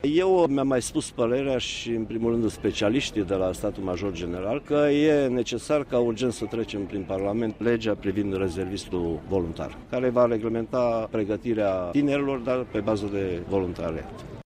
El a făcut aceste afirmaţii la Sibiu, unde aproximativ 200 de soldaţi profesionişti au depus, ieri, jurământul.